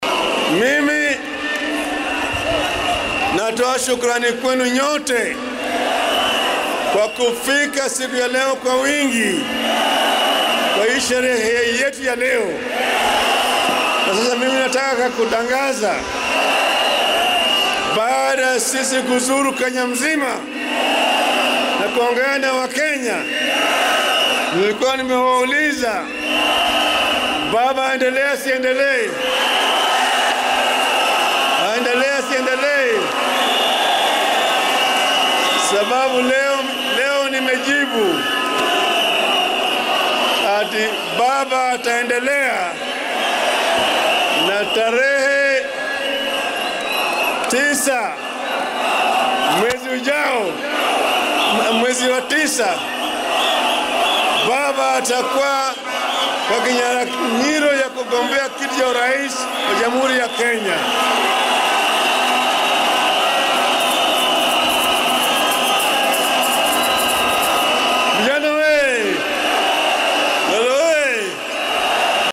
Arrinkan ayuu kaga dhawaaqay shirweynaha higsiga mideynta ee Azimio La Umoja oo lagu qabtay maanta garoonka cayaaraha Kasarani ee magaalada Nairobi.
Waxaa munaasabaddan kasoo qayb galay kumannaan kun oo dadweyne ah.